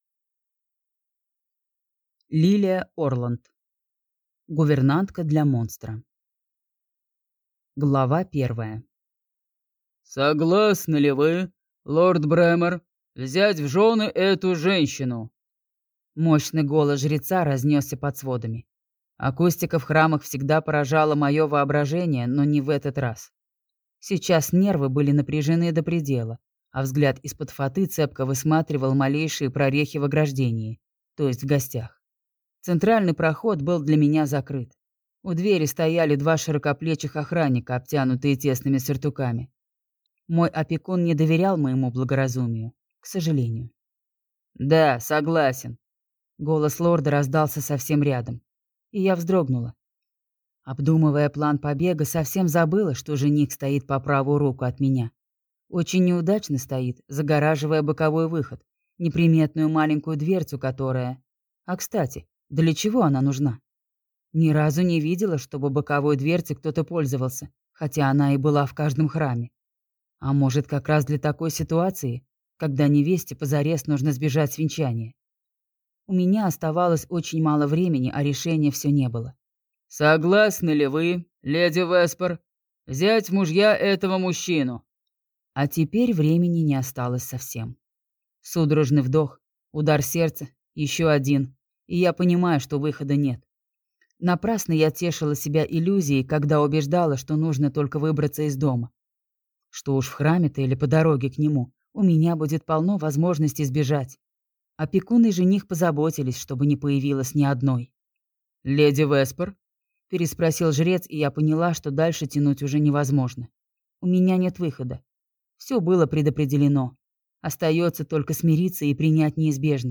Аудиокнига Гувернантка для монстра | Библиотека аудиокниг